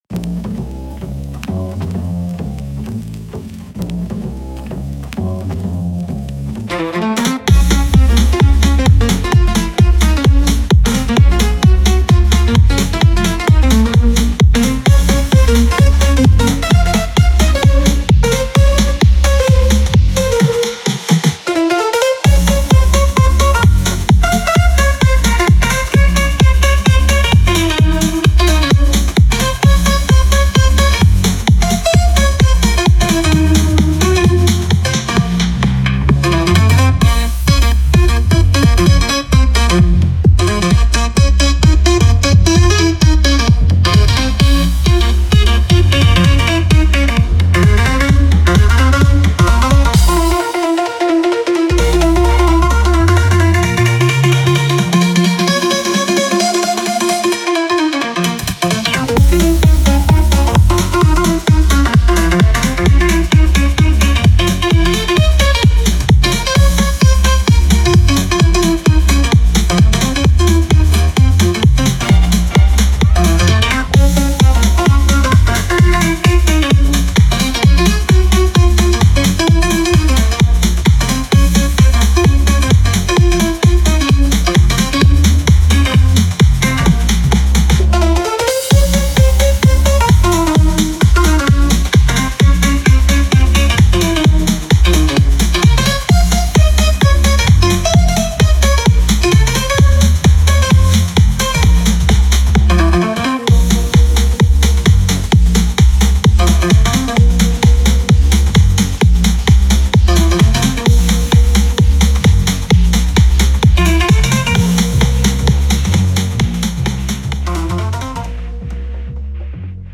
Experimental Jazz?
Genre Jazz